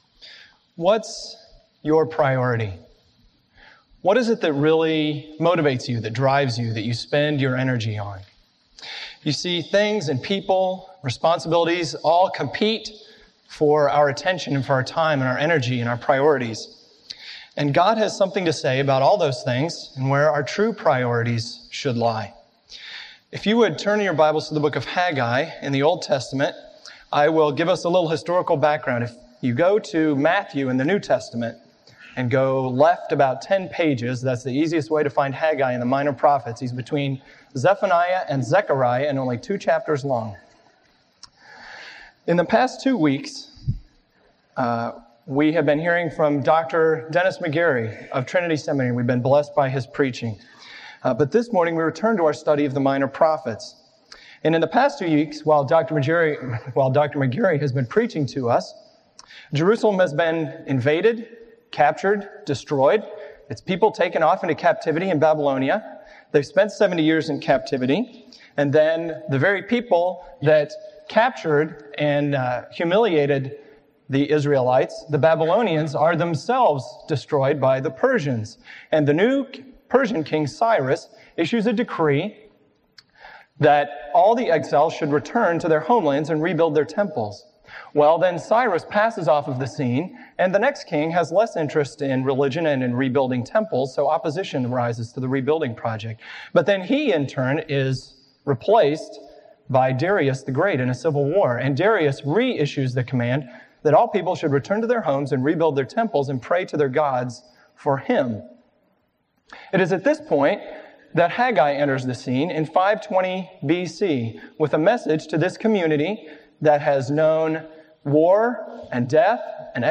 involved one sermon each on the Twelve Minor Prophets. Obviously, since these books are of varying lengths, from one chapter to fourteen chapters, these sermons are focused on the key message of each prophet, rather than a detailed examination of their words.